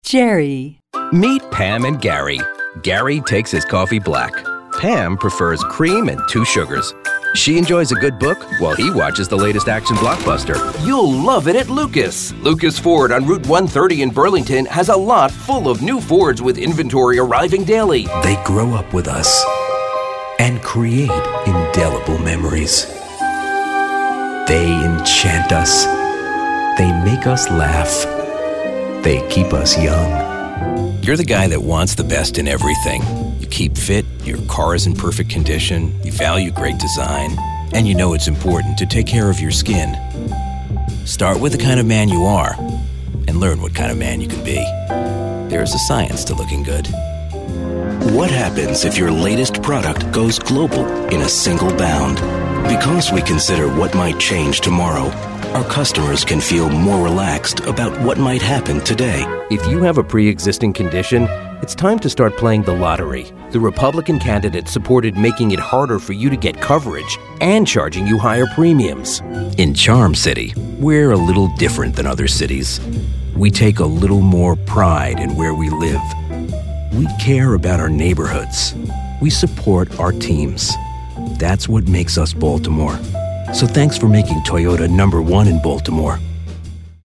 Showcase Demo
announcer, attitude, authoritative, classy, compelling, confident, motivational, sophisticated, thoughtful, warm, worldly
announcer, hard-sell, high-energy, promo, retail, upbeat
announcer, conversational, cool, friendly, jazzy, mature, raspy, real, sophisticated, warm
announcer, friendly, guy-next-door, homespun, mature, real, retail, upbeat
announcer, authoritative, classy, compelling, gritty, imaging, inspirational, movie-trailer, thoughtful
announcer, friendly, guy-next-door, humorous, narrative, sincere, warm